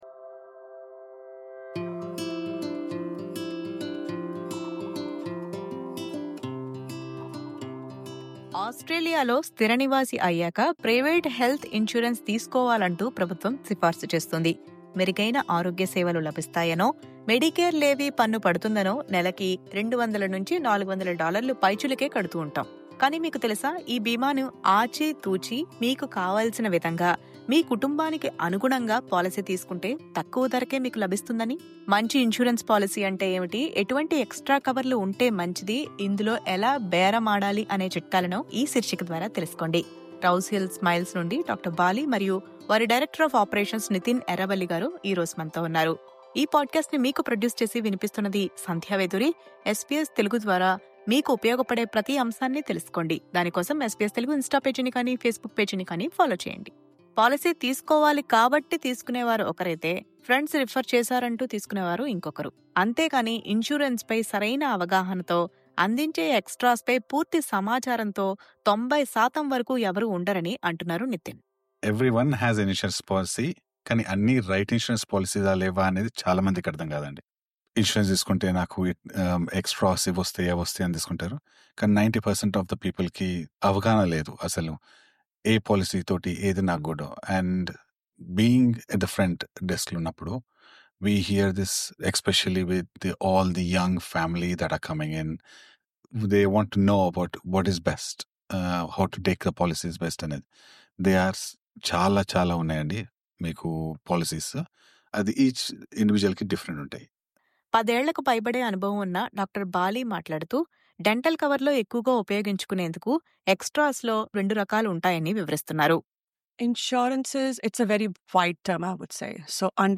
at SBS Telugu studio for a chat on choosing the right private health insurance.